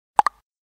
Звуки сообщений
На данной странице вы можете прослушать онлайн короткие звуки уведомлений для sms на  телефон android, iPhone и приложения.